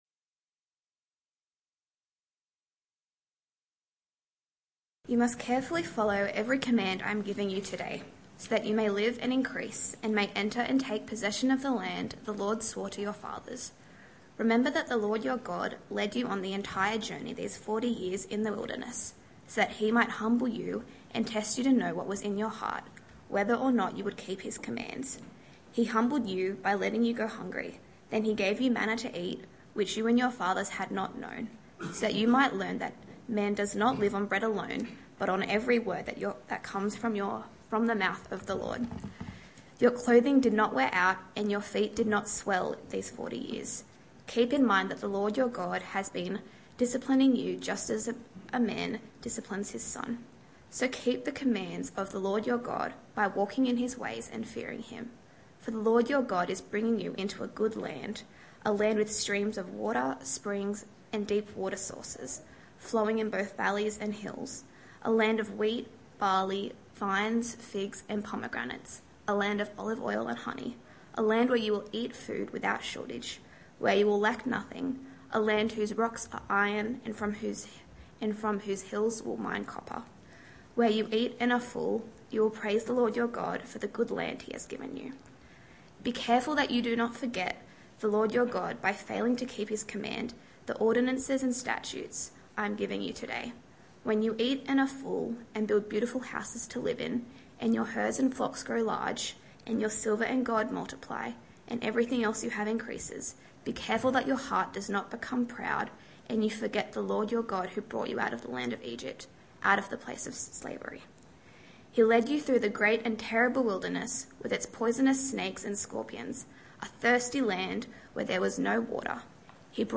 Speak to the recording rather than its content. Morning Church - Evening Church